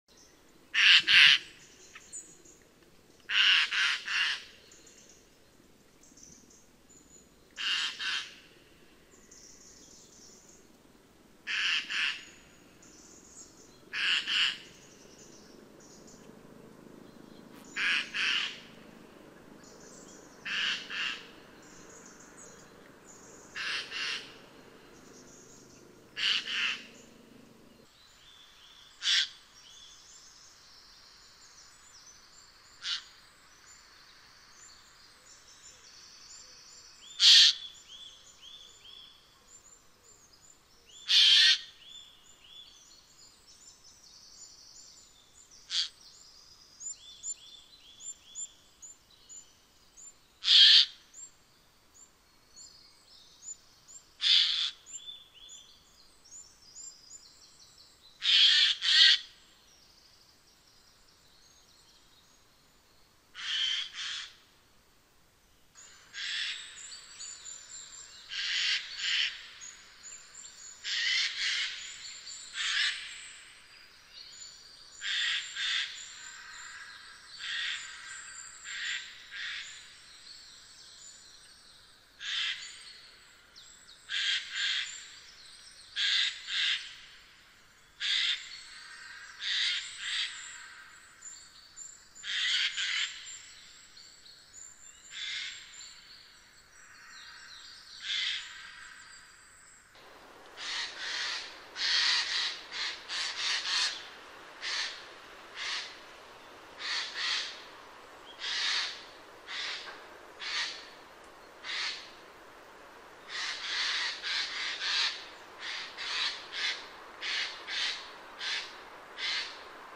Ghiandaia
Arrendajo-o-Arrendajo-Euroasiático-Cantando-Sonido-para-Llamar-El-Mejor.mp3